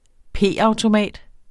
Udtale [ ˈpeˀɑwtoˌmæˀd ]